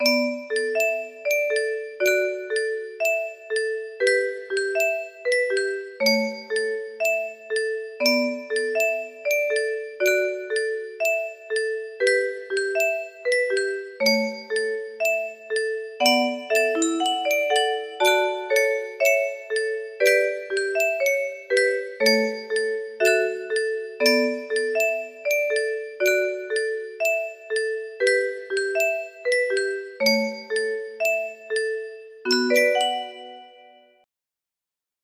baibaba bimba music box melody